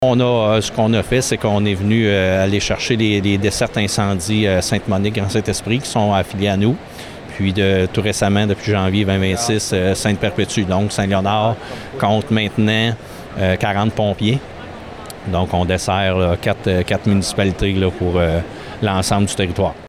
Les effectifs de pompiers serviront à la Municipalité, mais comme l’a expliqué le maire, Laurent Marcotte, elle offrira aussi des services à des villages des alentours.